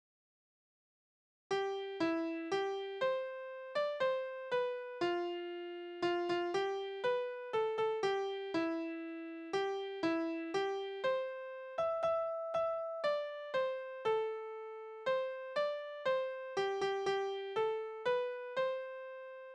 Berufslieder: Der Fischer
Tonart: C-Dur
Taktart: 4/4
Tonumfang: Oktave
Besetzung: vokal